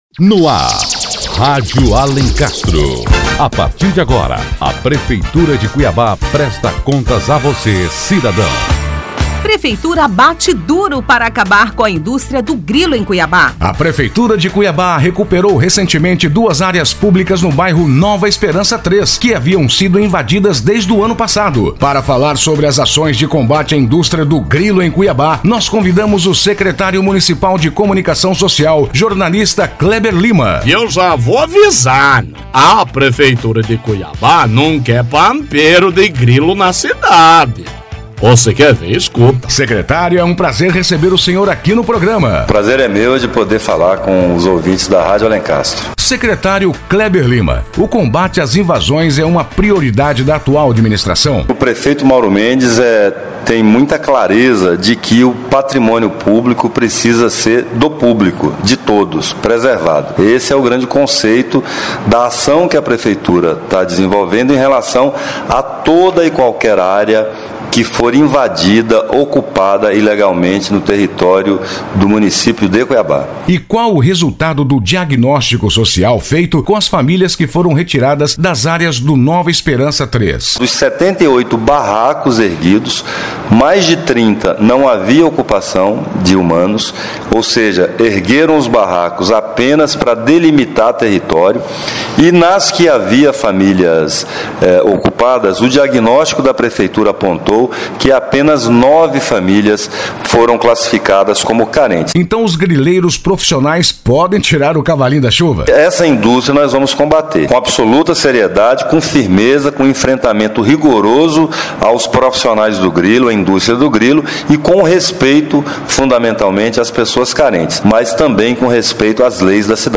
Notícias / 45º Programa 20 de Fevereiro de 2014 15h51 Fim da indústria de grilo na capital Ouça a entrevista com o Secretário Municipal de Comunicação, Kleber Lima, e saiba quais são as ações de combate a indústria de grilo em Cuiabá.